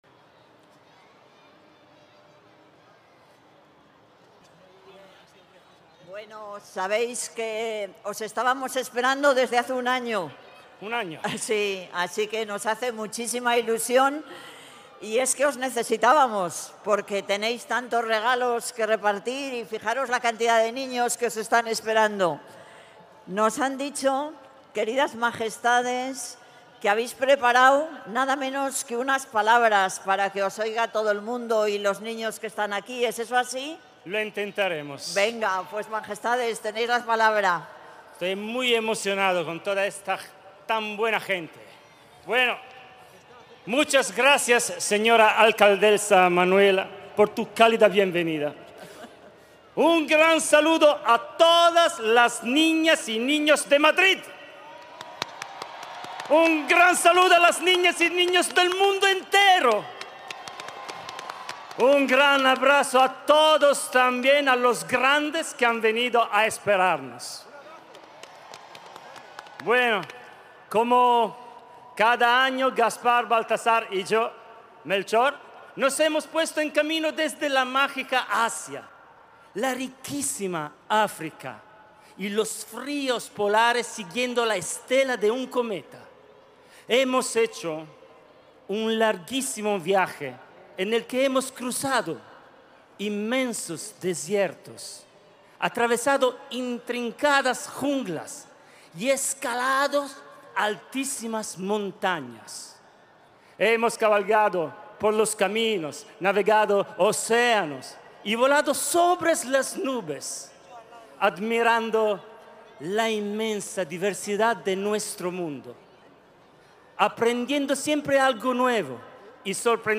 Nueva ventana:Bienvenida de la alcaldesa a los Reyes Magos
ActoCierreCabalgataCarmenaYReyesMagos-05-01.mp3